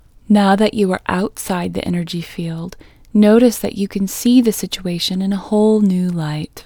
OUT Technique Female English 17